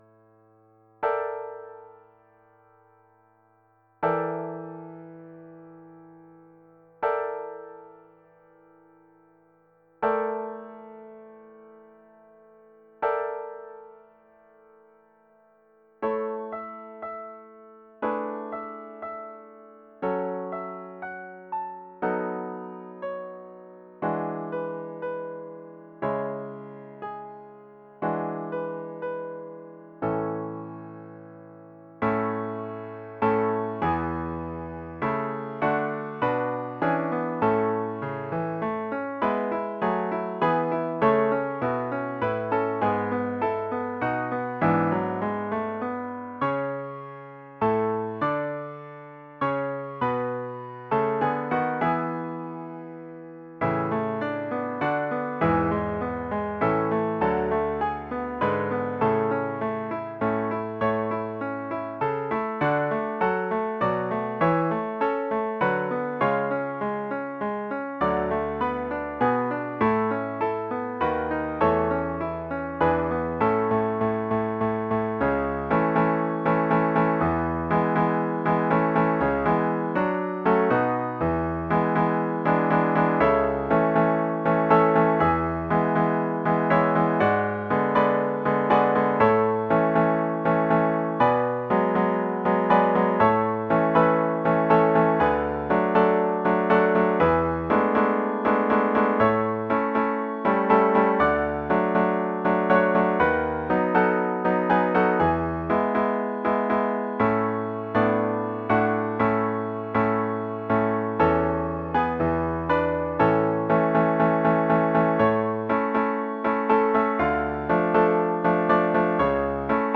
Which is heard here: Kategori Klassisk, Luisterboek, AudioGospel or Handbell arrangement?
Handbell arrangement